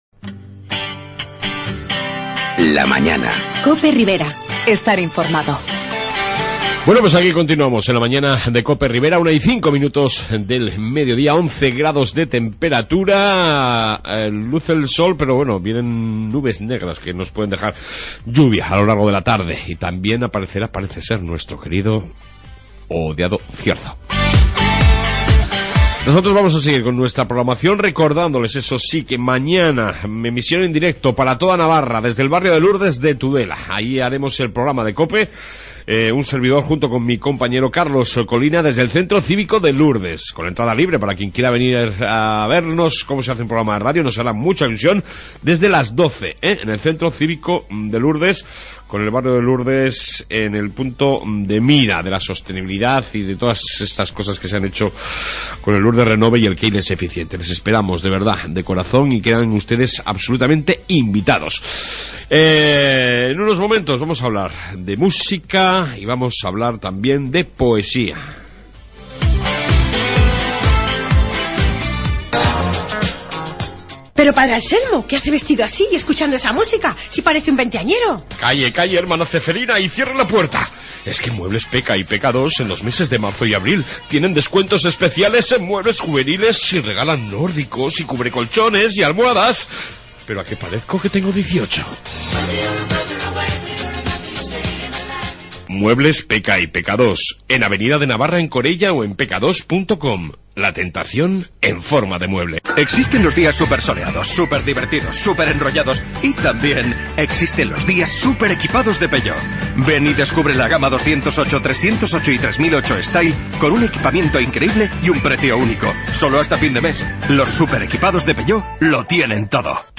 AUDIO: En esta 2 Parte, entreviata